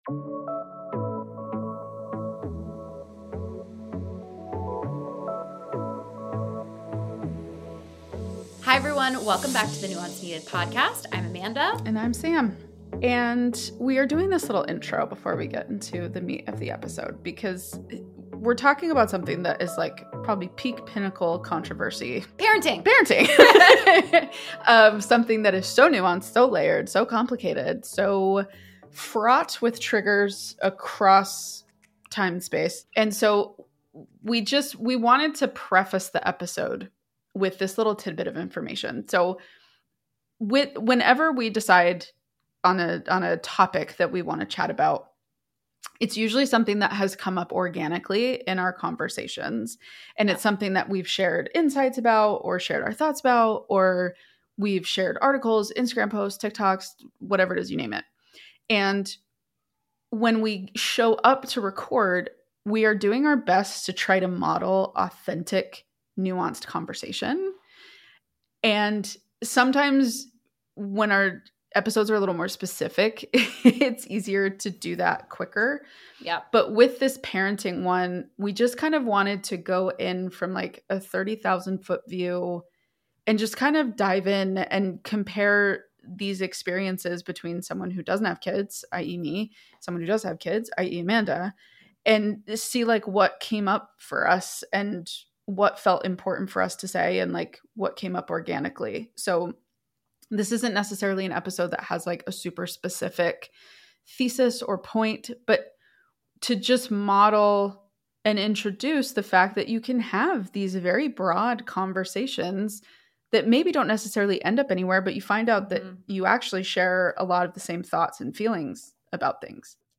In this conversation, we tackle: